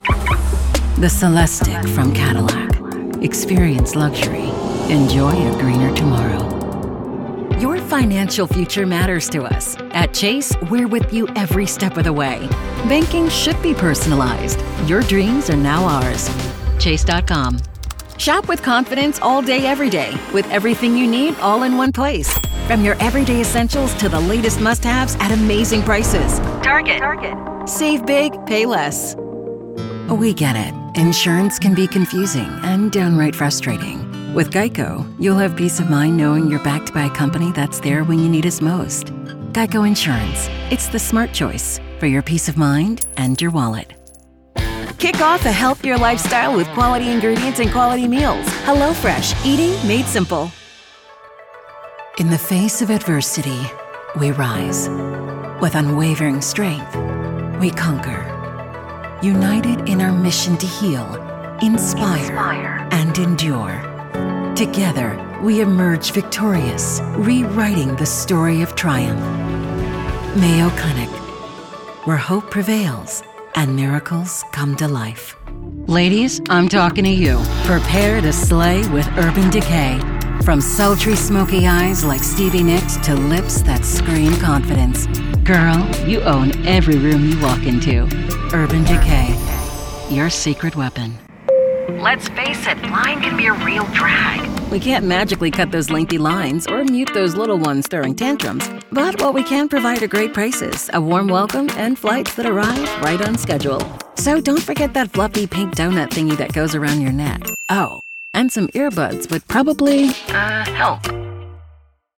British Female Voice Over Artists & Actors | Affordable & Hire
Warm and deep for narrations, fun and cheer for commercials, smooth and professional for presentations.